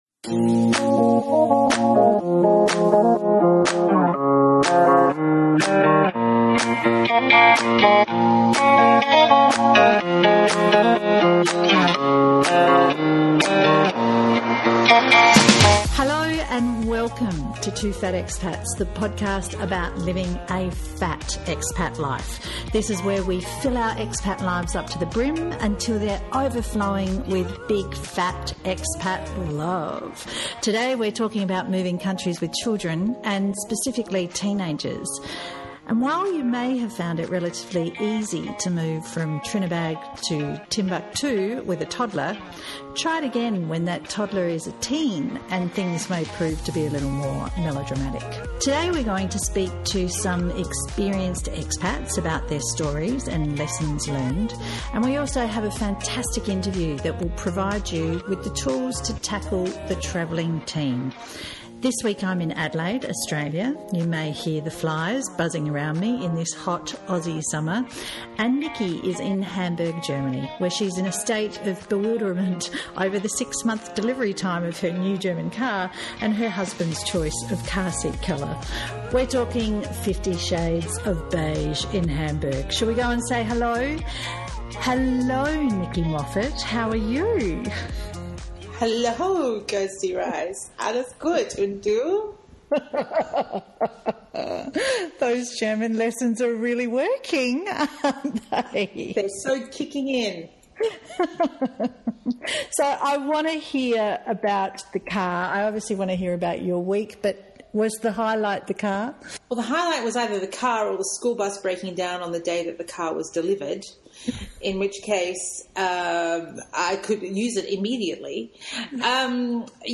Both interviews are insightful and full of tips for expats on the move with teens.